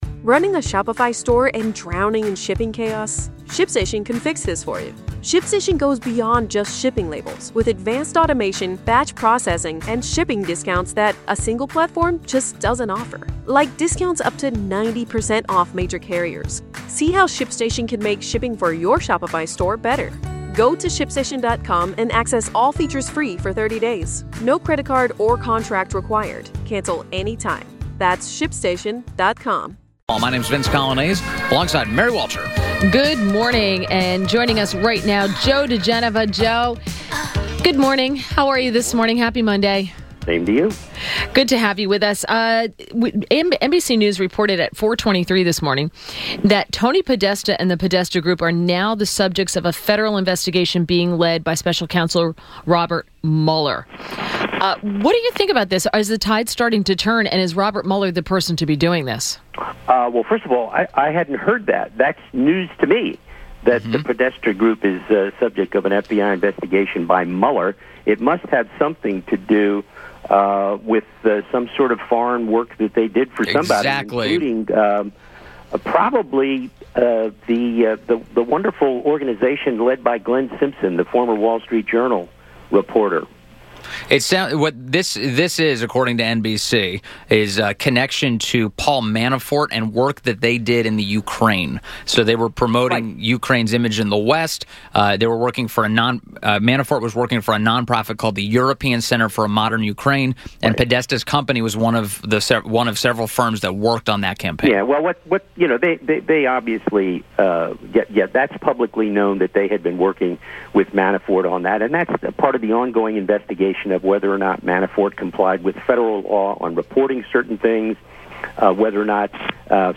INTERVIEW – JOE DIGENOVA – legal analyst and former U.S. Attorney to the District of Columbia